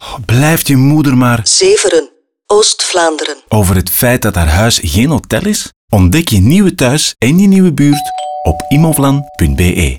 Radiospot_Immovlan_Zeveren